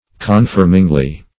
confirmingly - definition of confirmingly - synonyms, pronunciation, spelling from Free Dictionary Search Result for " confirmingly" : The Collaborative International Dictionary of English v.0.48: Confirmingly \Con*firm"ing*ly\, adv.
confirmingly.mp3